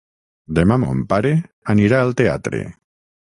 Pronúnciase como (IPA)
/mun/